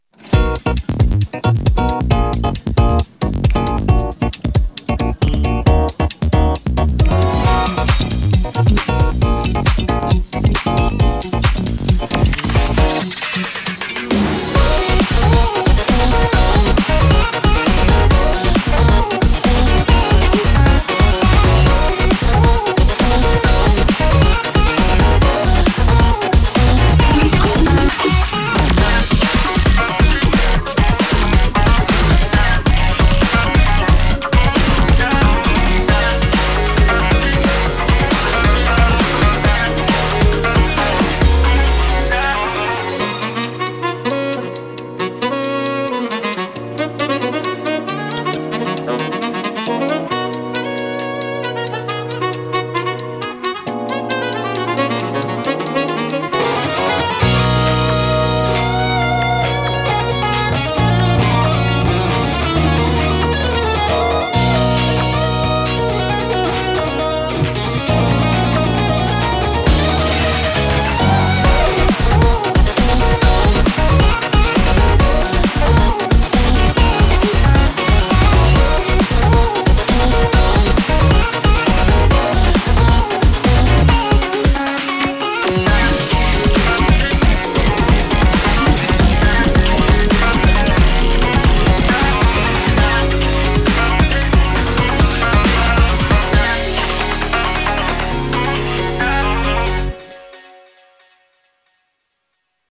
BPM135
Audio QualityPerfect (High Quality)
This song has some very strage rhythms sometimes.